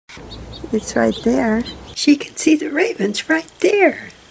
Throughout much of the video there will be a clip of Songbird speak followed by my attempt to imitate the Songbird accent while speaking what seem to me to be the words they just spoke.